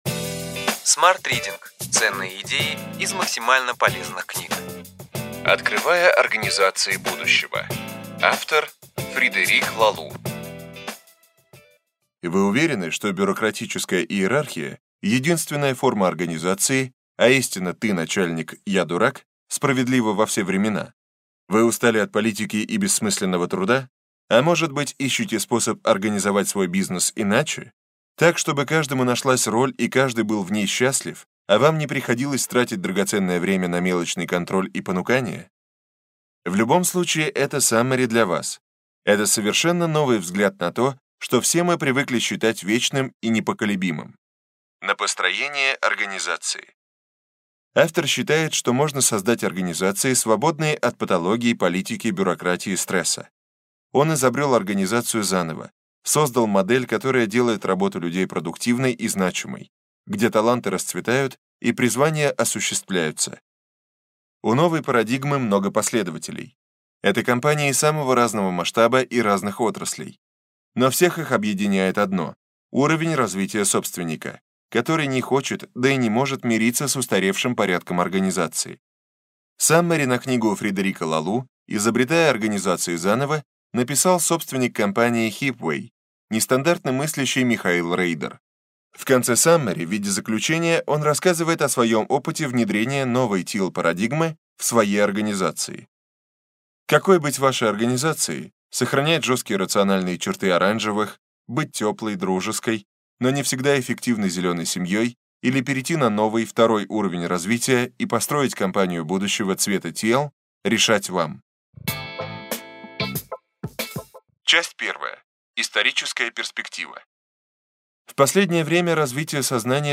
Аудиокнига Ключевые идеи книги: Открывая организации будущего.